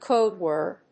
アクセントcóde wòrd